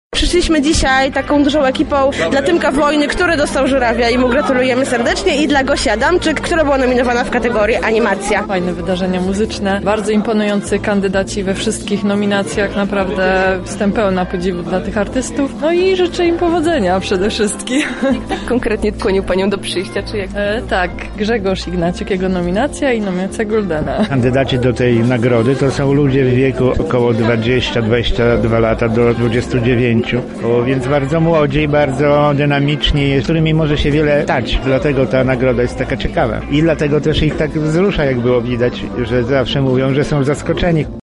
Uczestnicy spotkania podzielili się z nami swoimi wrażeniami: